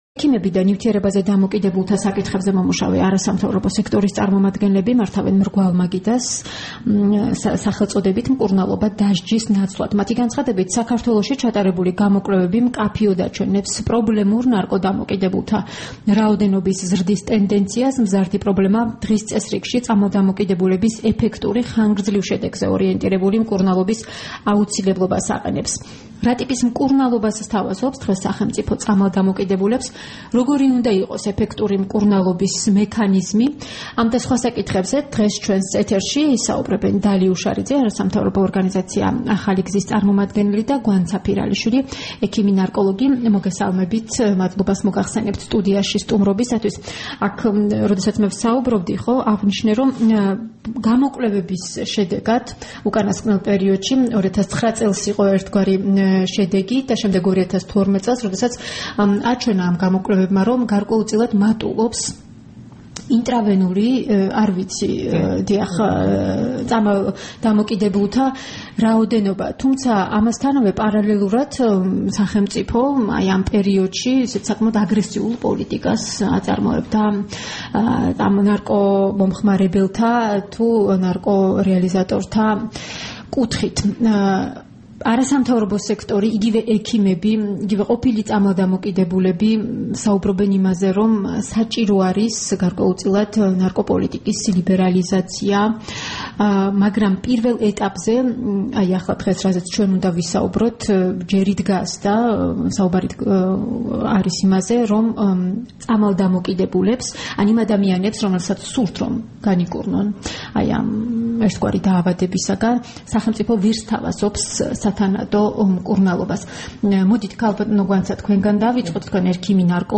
რადიო თავისუფლების დილის გადაცემის სტუმრები
ექიმი ნარკოლოგი